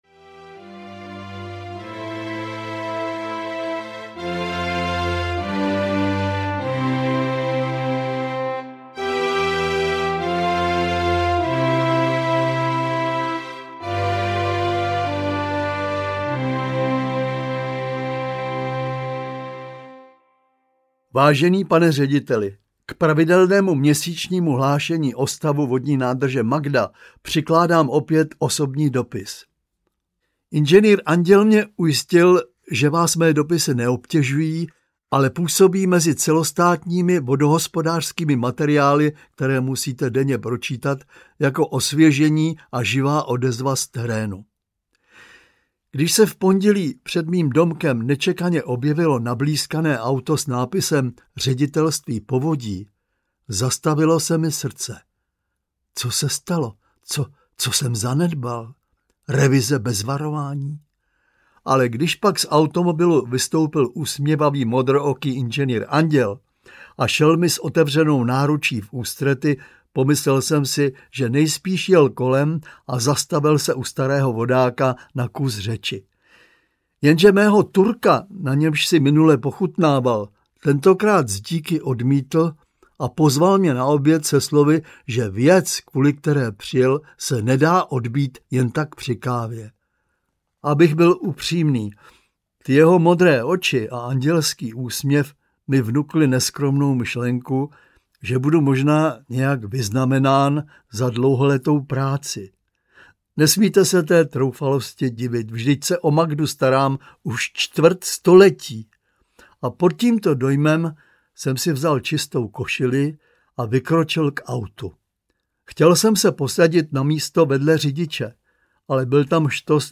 Strážce nádrže audiokniha
Ukázka z knihy
• InterpretZdeněk Svěrák, Jaroslav Uhlíř